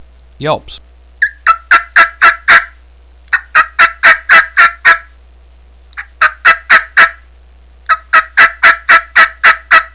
Yellow Yelper 3 Reed, 3 Cutt Mouth Call
yythreethreeyelps10.wav